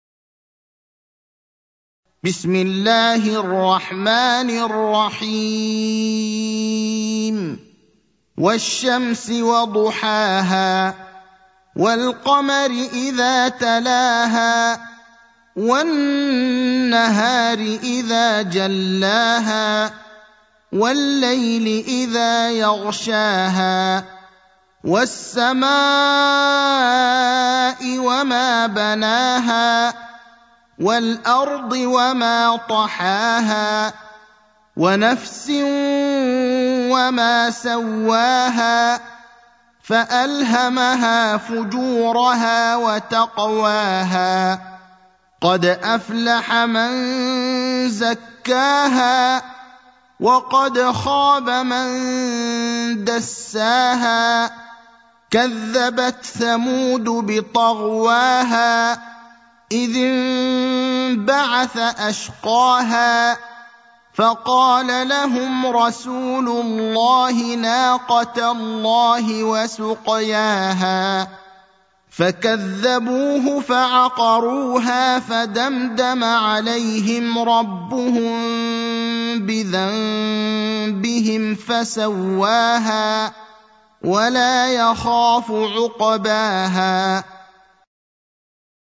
المكان: المسجد النبوي الشيخ: فضيلة الشيخ إبراهيم الأخضر فضيلة الشيخ إبراهيم الأخضر الشمس (91)